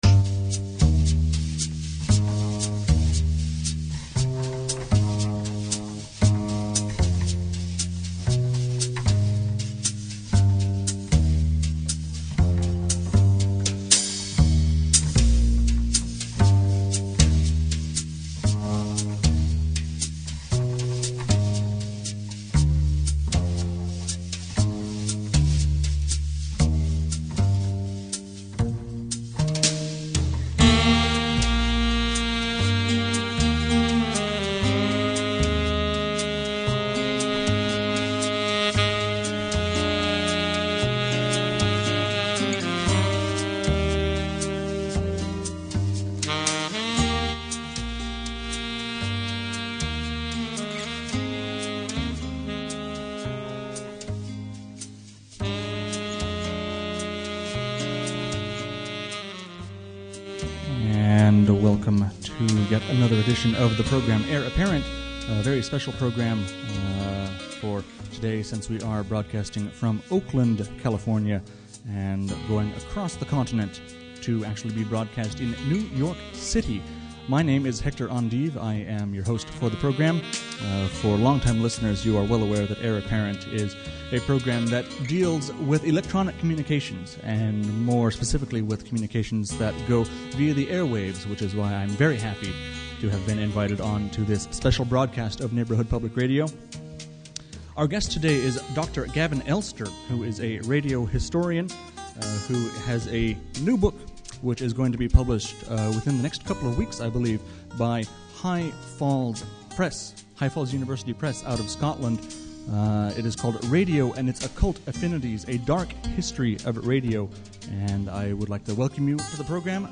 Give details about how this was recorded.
2008: Live Neighborhood Public Radio Broadcast, March 22nd 2008.